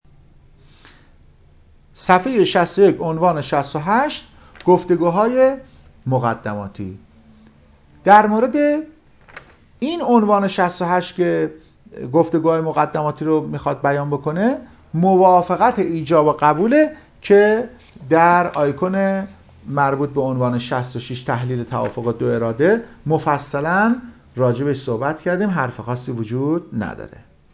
تدریس حقوق مدنی